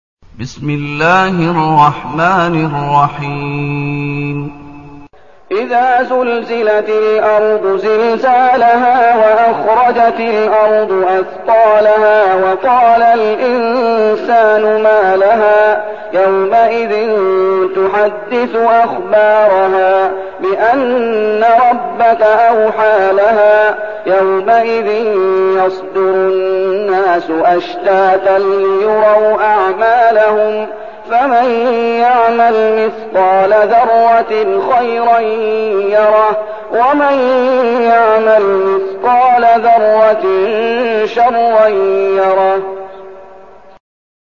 المكان: المسجد النبوي الشيخ: فضيلة الشيخ محمد أيوب فضيلة الشيخ محمد أيوب الزلزلة